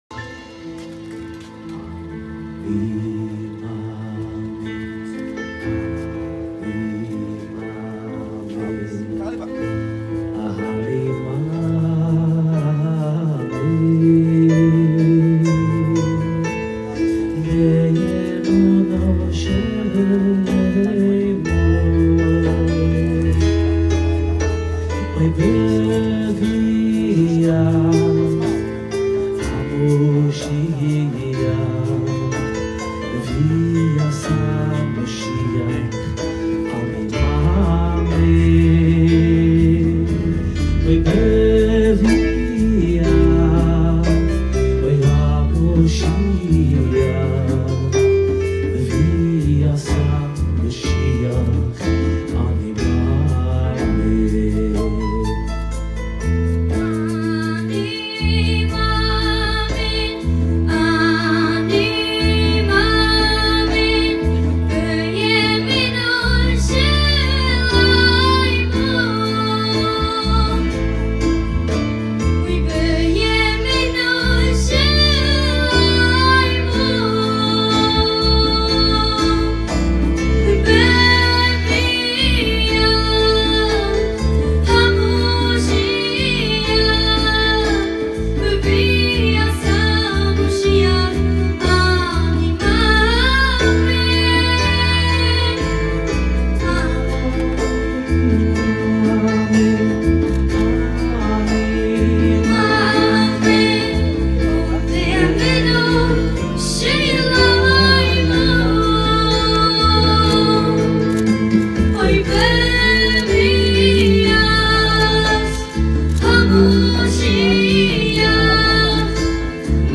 בהכנה לבר מצוה